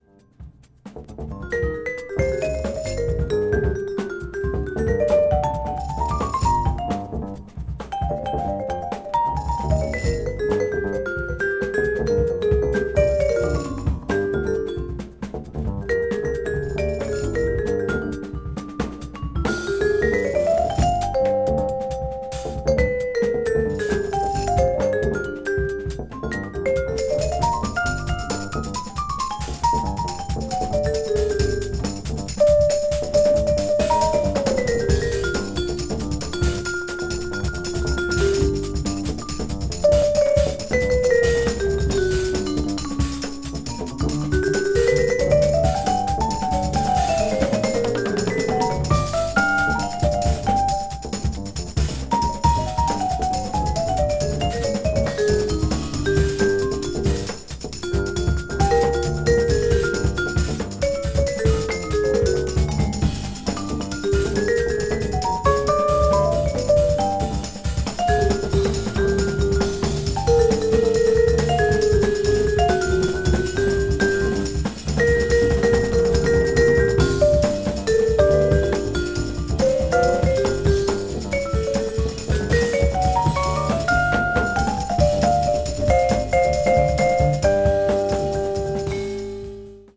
エスニック・テイストも加味した超高水準のジャズ・アルバム